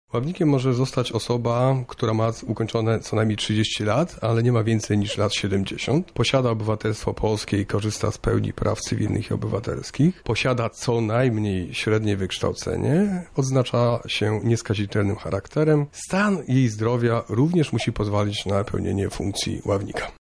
„Ławnik jest czynnikiem społecznym w wymiarze sprawiedliwości” mówi sędzia Dariusz Abramowicz, rzecznik Sądu Okręgowego w Lublinie